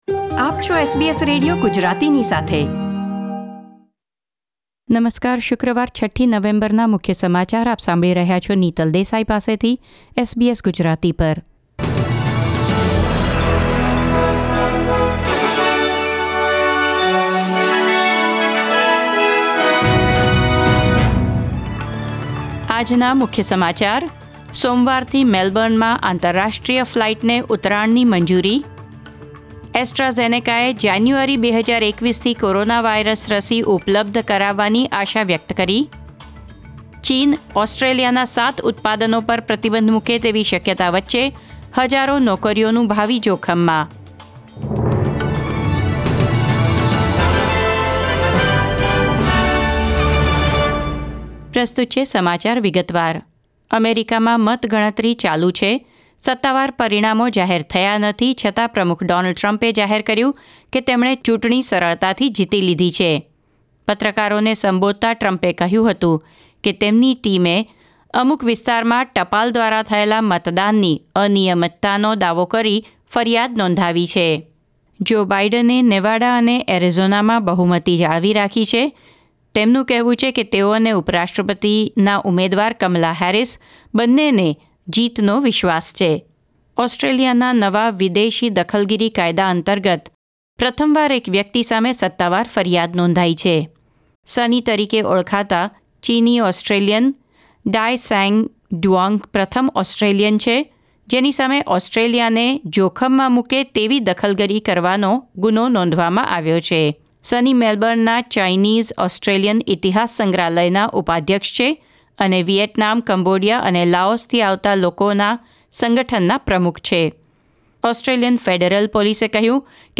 SBS Gujarati News Bulletin 6 November 2020